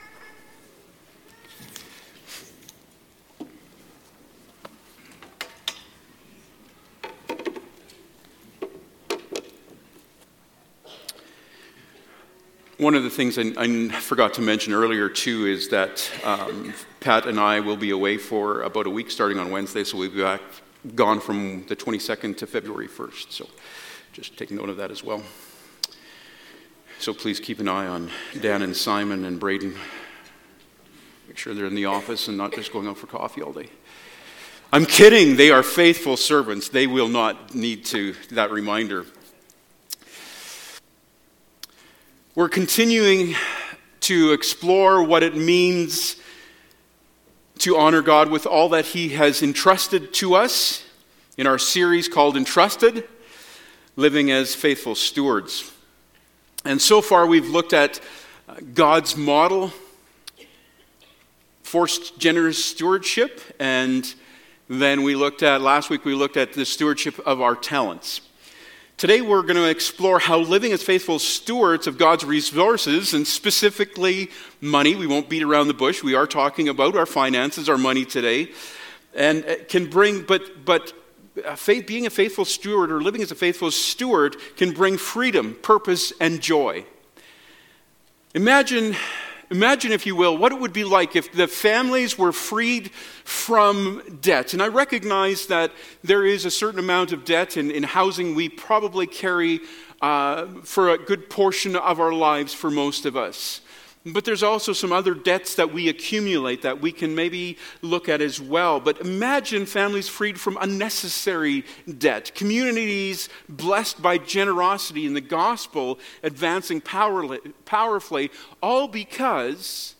Service Type: Sunday Morning Topics: Stewardship